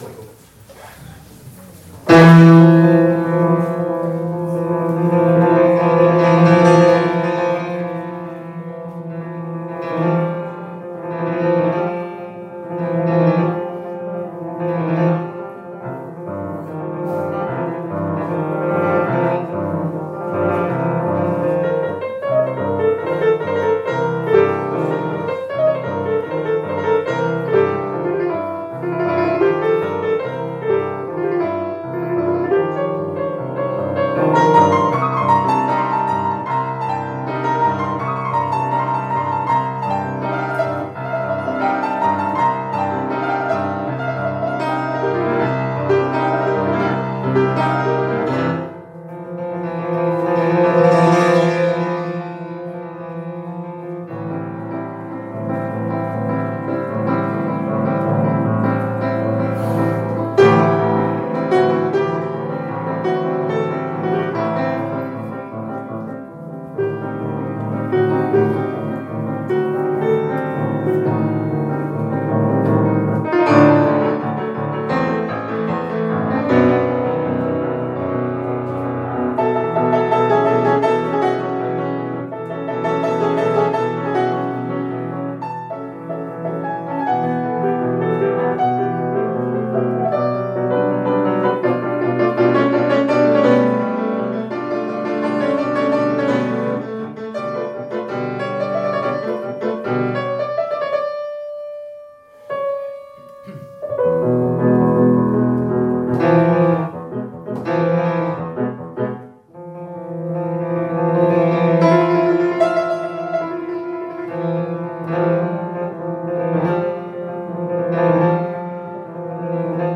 < Back Stagione Invernale 2025-2026 Data domenica 17:30 22 febbraio 2026 Sala Consiliare Note Un menù espanol per Baritono e Pianoforte.